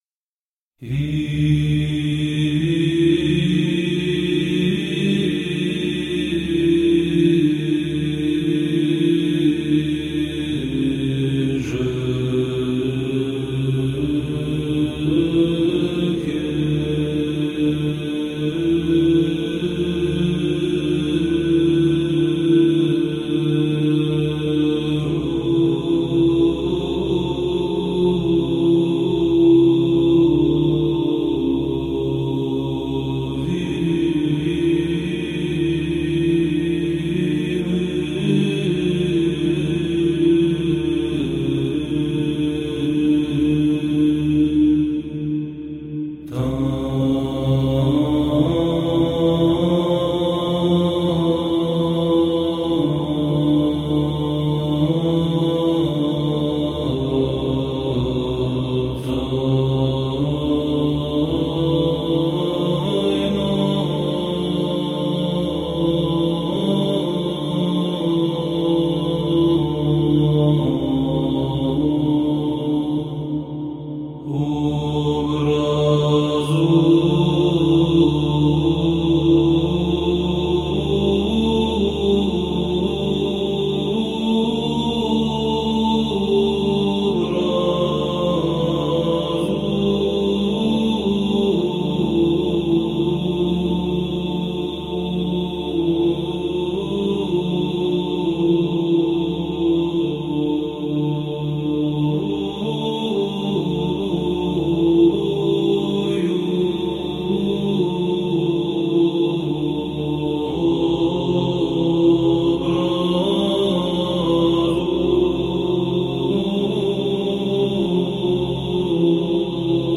Болгарский распев на подобен